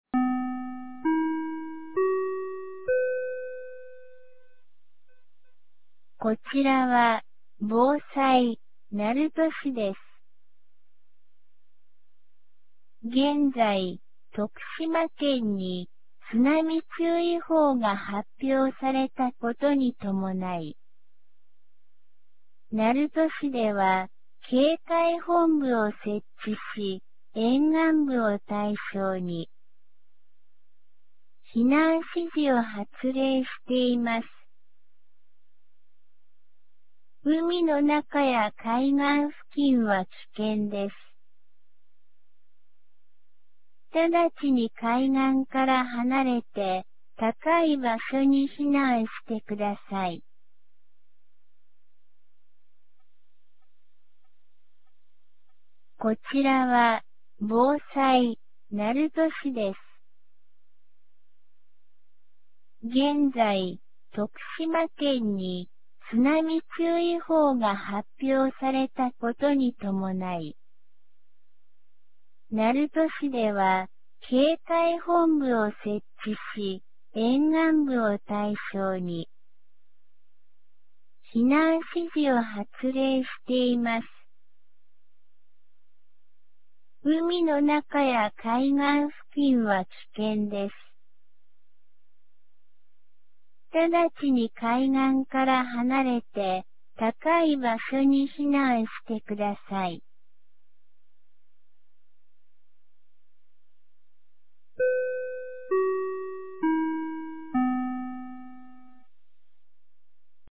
2025年07月30日 14時51分に、鳴門市より全地区へ放送がありました。